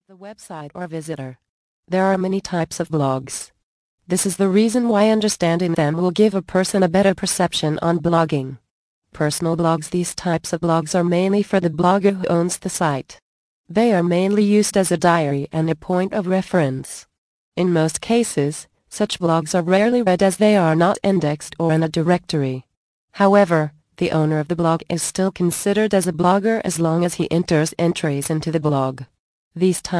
Indispensable Almanac of Internet Marketing mp3 Audio Book 5